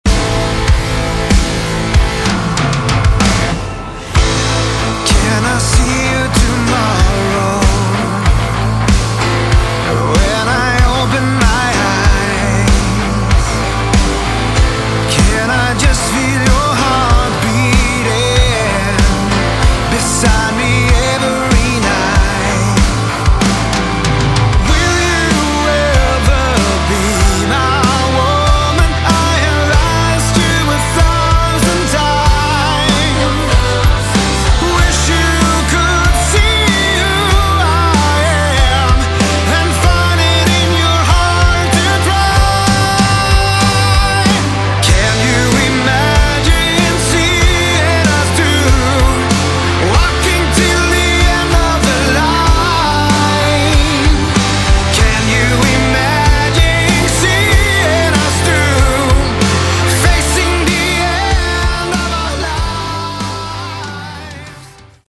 Category: Melodic Rock
vocals
guitar
keyboards
bass guitar
drums